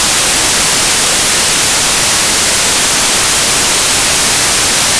static.wav